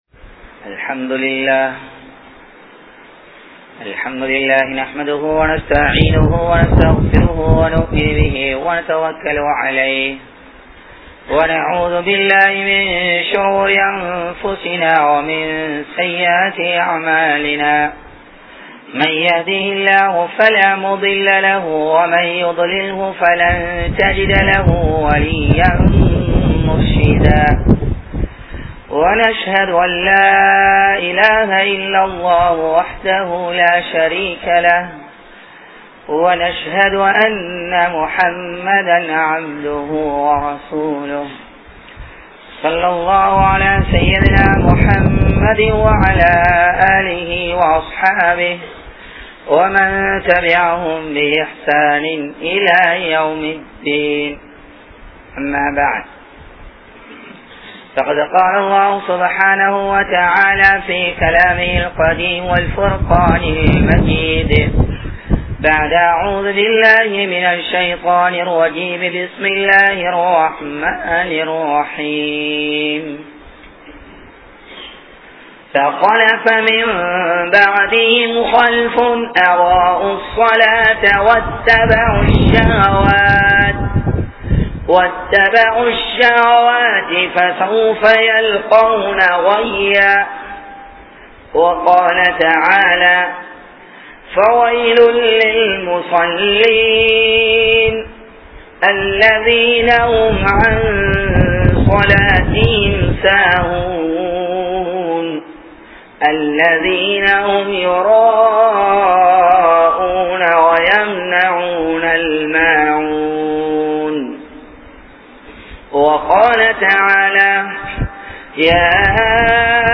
Tholuhaium Indraya Manitharhalum (தொழுகையும் இன்றைய மனிதர்களும்) | Audio Bayans | All Ceylon Muslim Youth Community | Addalaichenai
Galle, Kanampittya Masjithun Noor Jumua Masjith